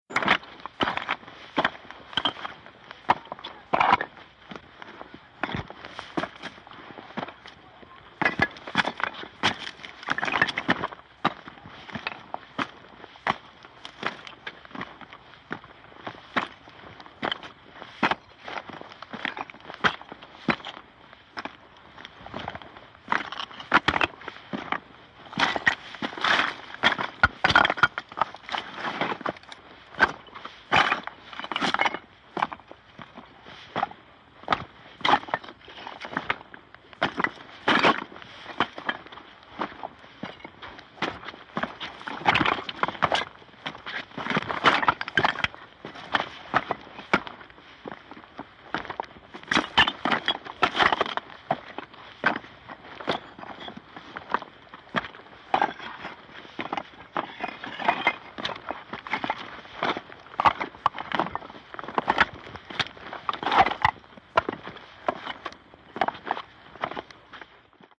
FOLEY FOOTSTEPS SHALE: EXT: Walking on shale on side of mountain, body movement, shale falling away.